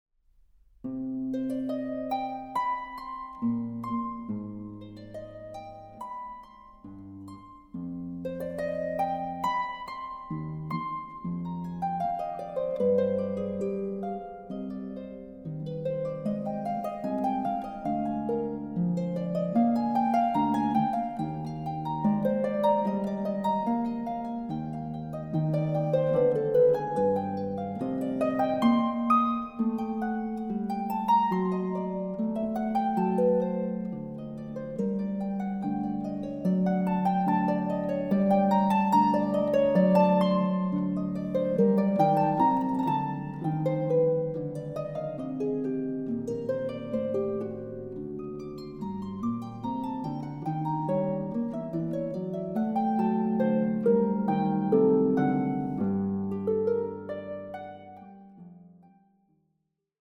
Harp
Recording: Festeburgkirche Frankfurt, 2024